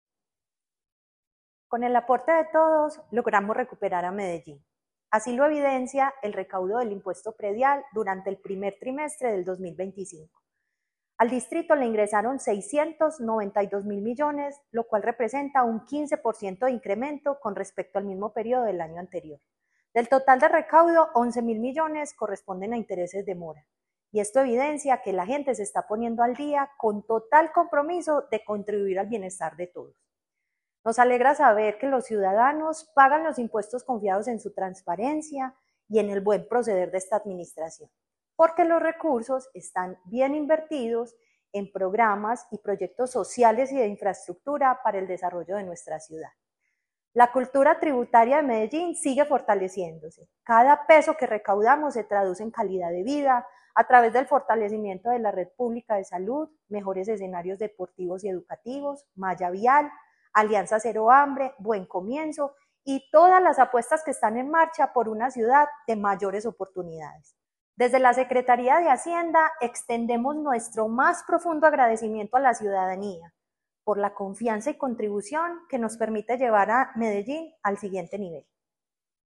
Palabras de Liliana Zapata Jaramillo, subsecretaria de Ingresos